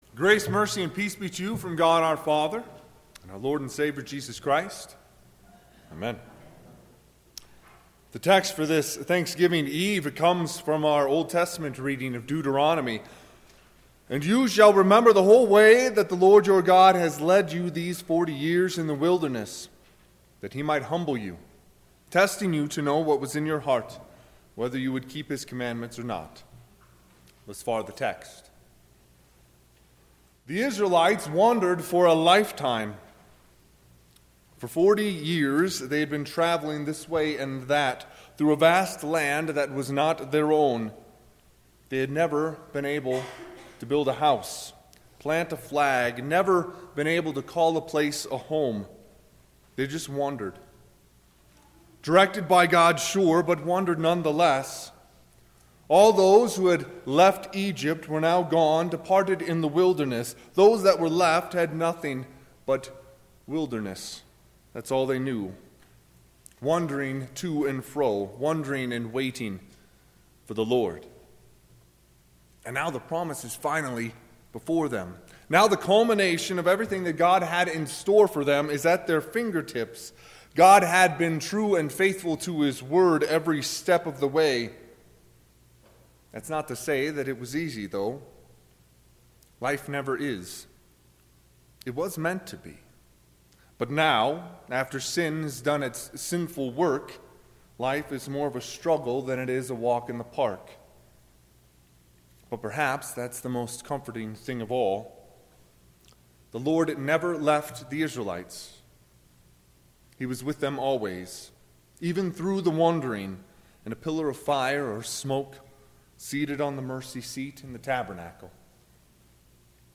Thanksgiving Eve Service
Sermon – 11/27/2019